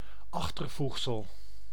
Ääntäminen
IPA: /'aɣ.tər.vuɣ.səl/